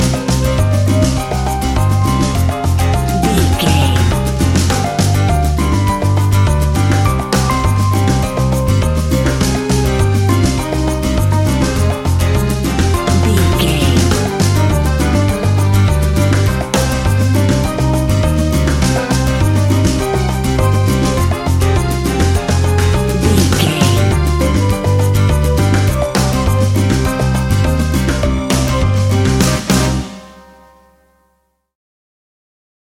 An exotic and colorful piece of Espanic and Latin music.
Ionian/Major
D
flamenco
maracas
percussion spanish guitar